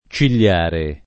cigliare [ © il’l’ # re ] → ciliare